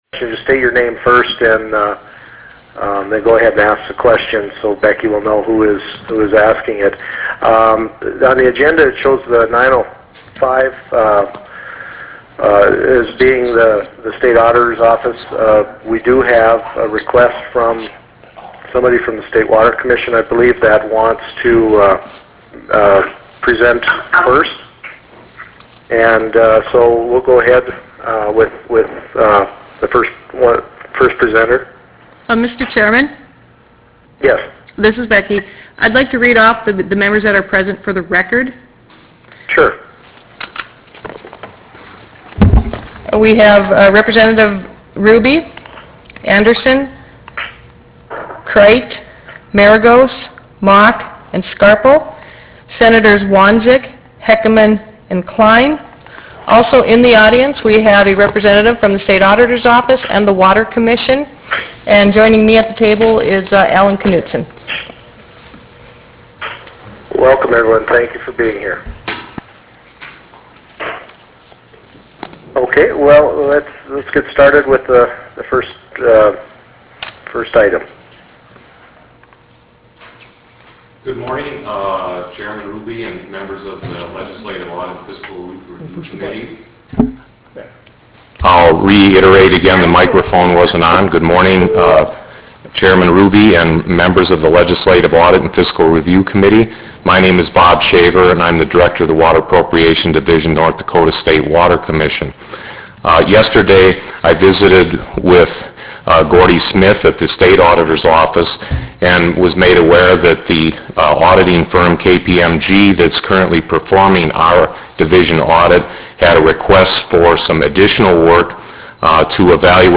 Prairie Room State Capitol Bismarck, ND United States
Teleconference Meeting